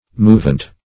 Search Result for " movent" : The Collaborative International Dictionary of English v.0.48: Movent \Mo"vent\, a. [L. movens, p. pr. of movere.
movent.mp3